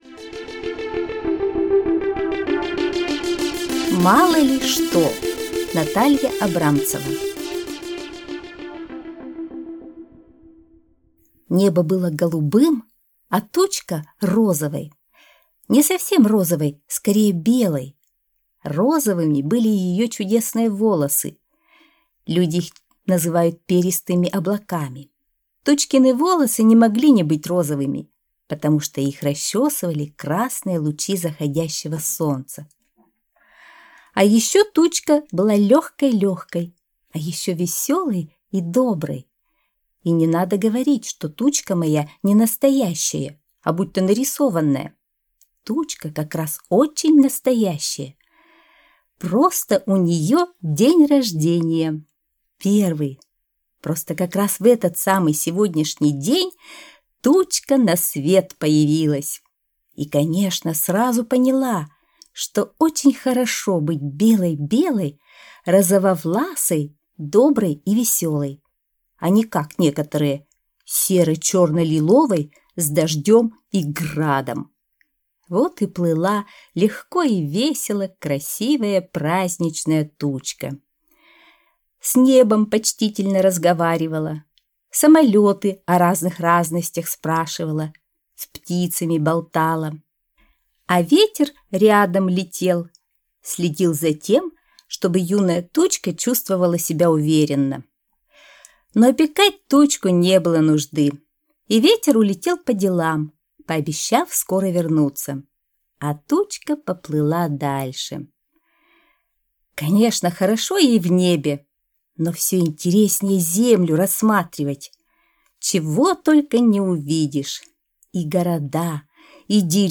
Аудиосказка «Мало ли что»